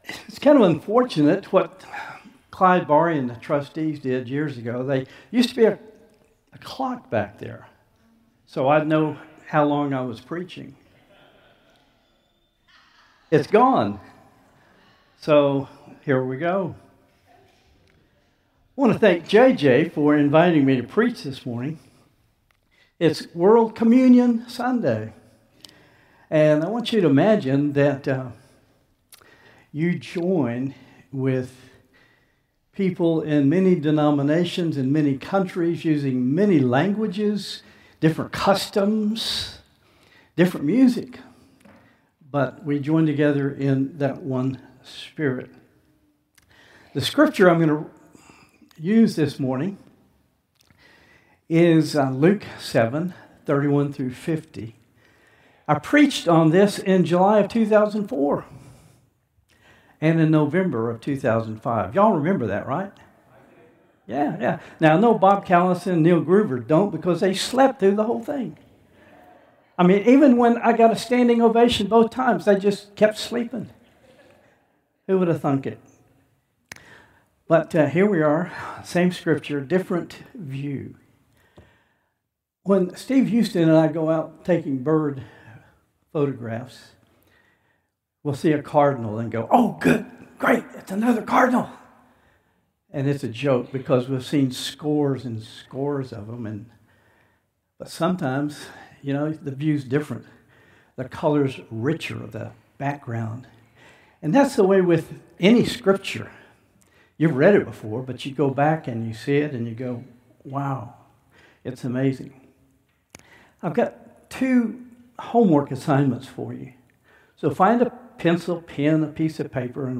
Contemporary Service 10/5/2025